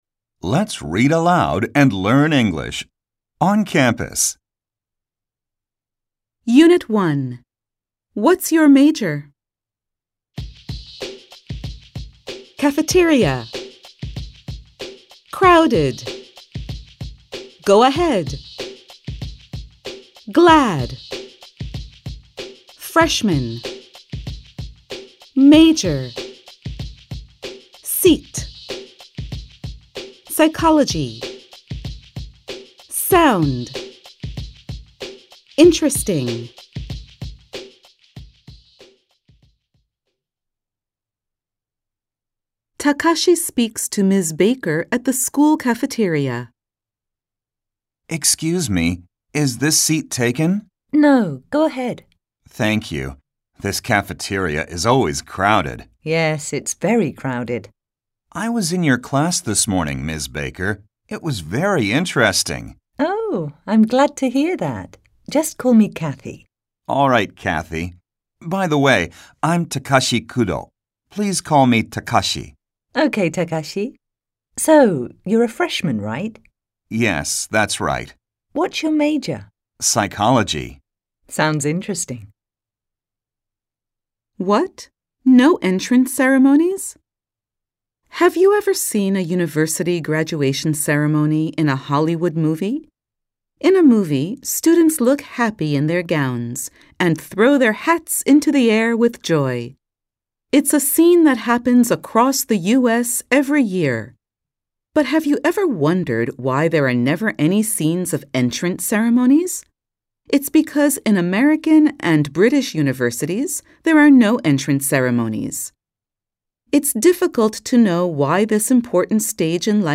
吹き込み Amer E ／ Brit E ／ 他